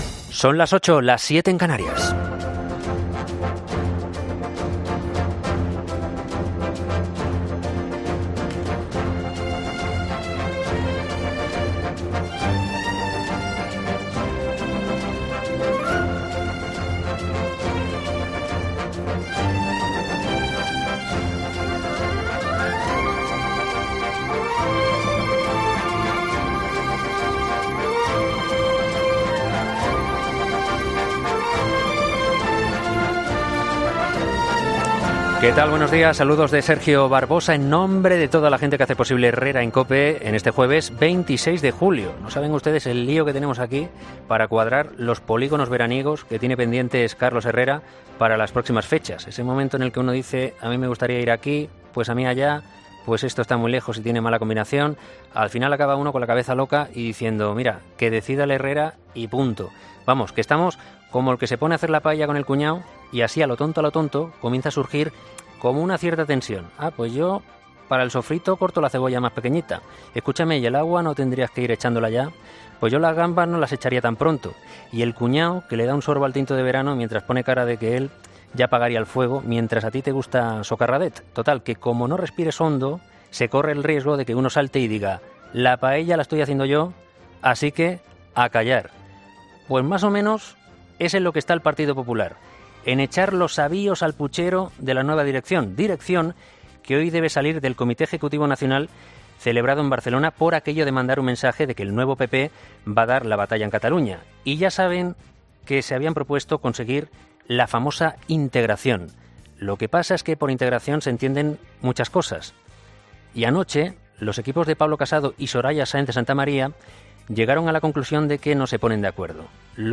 Monólogo de las 8